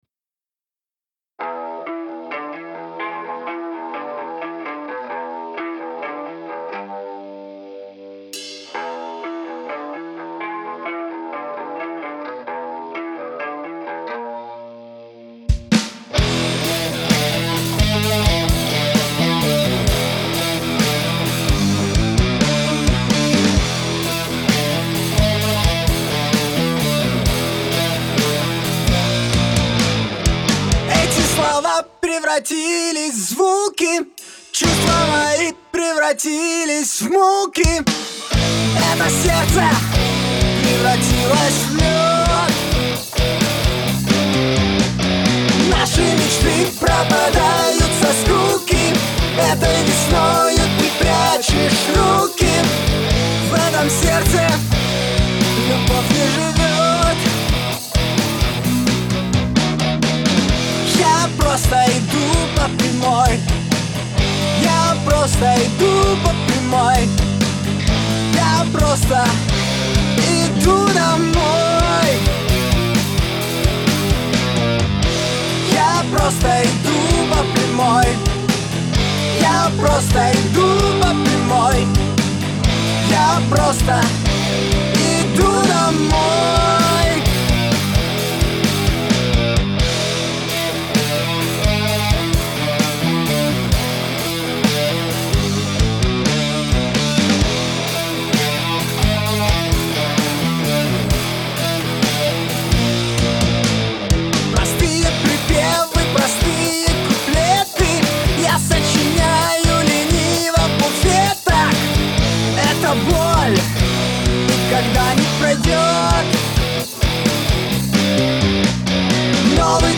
Первый опыт (Рок)
Решил попробовать записать коллектив в котором сейчас играю.